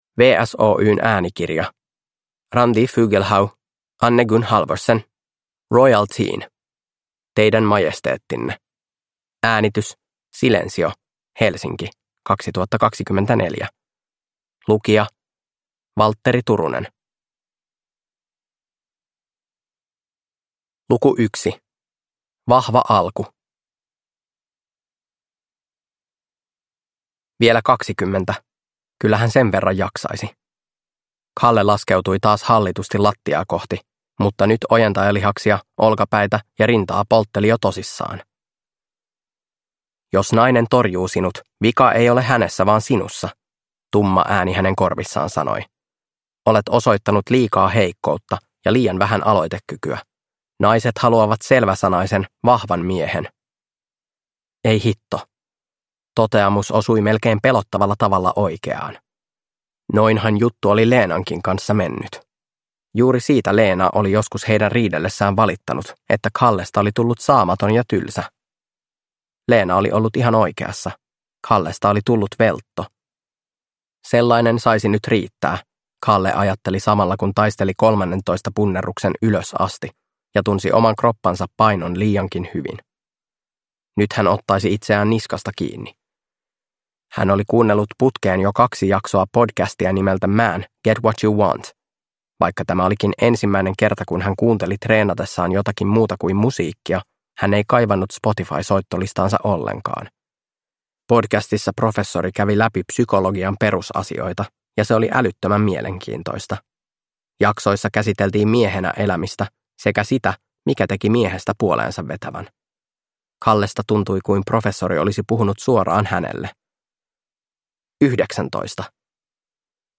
Barn & ungdom Feelgood Njut av en bra bok Unga vuxna